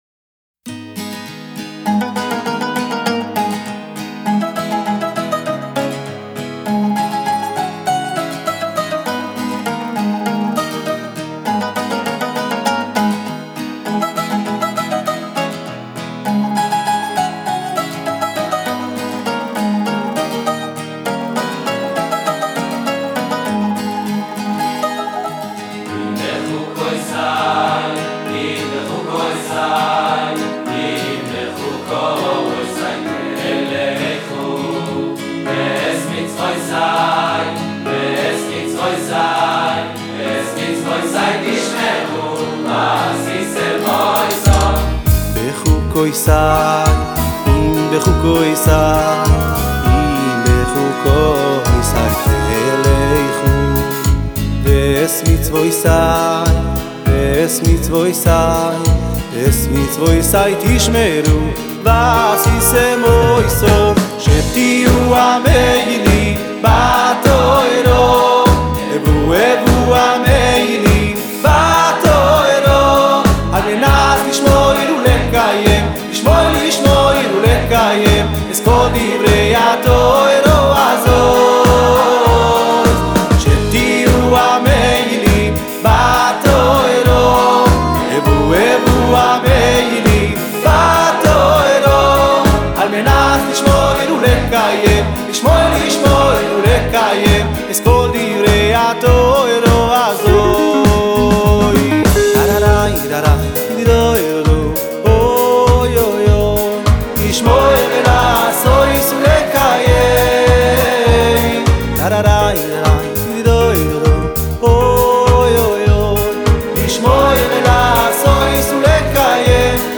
והוא מופיע כאמור כזמר וקלידן